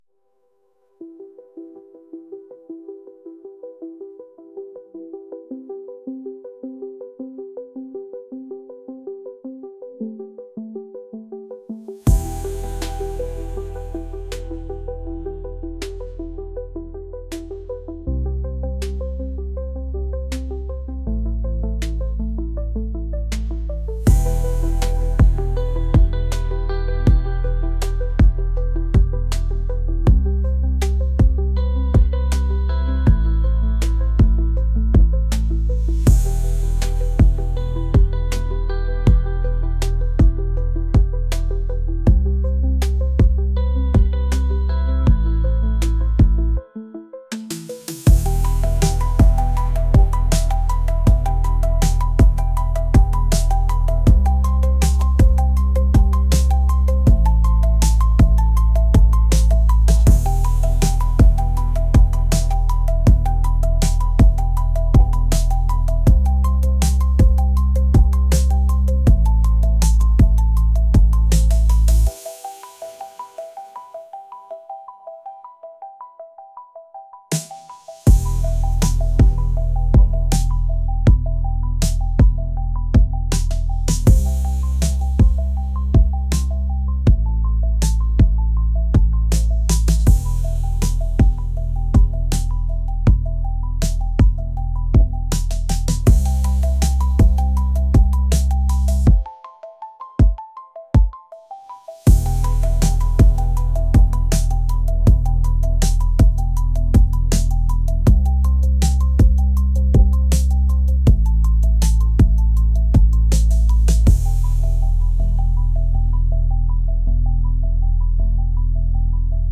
dreamy | atmospheric